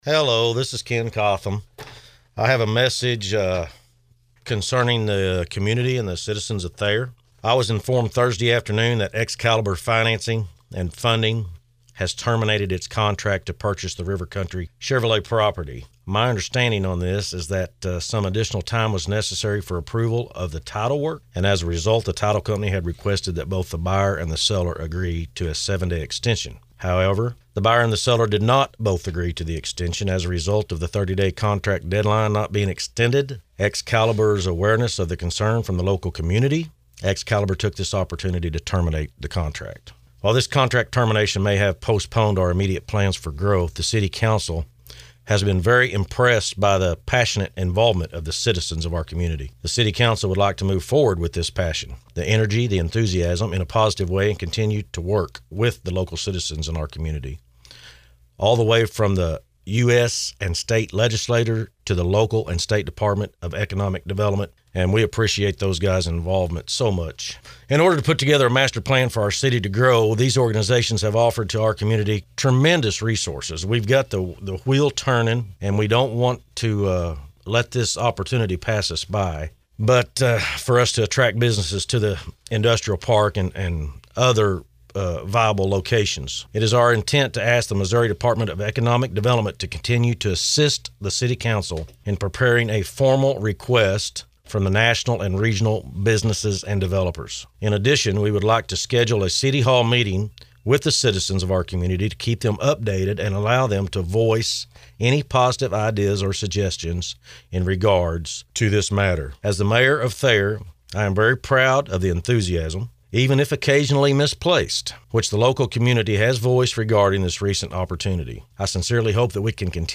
Listen to the Mayor’s entire statement here: